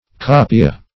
Search Result for " kapia" : The Collaborative International Dictionary of English v.0.48: Kapia \Ka"pi*a\, n. [Native name.]